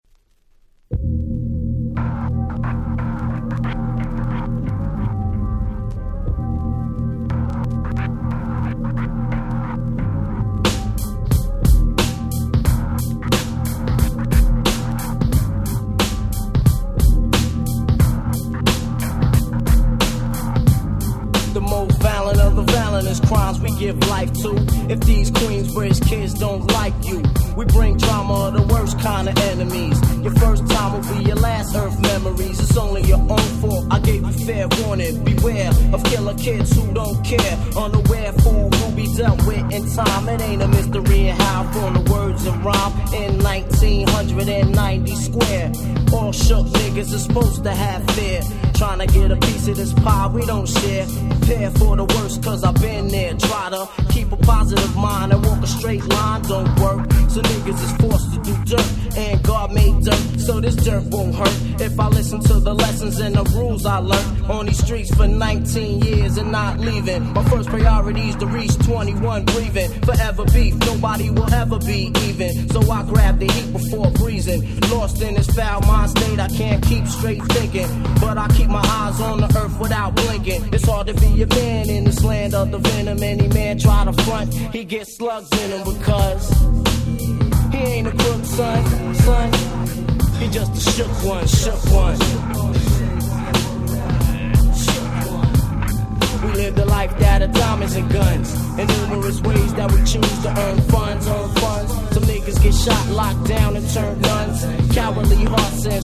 94' Smash Hit Hip Hop !!